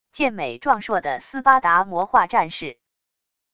健美壮硕的斯巴达魔化战士wav音频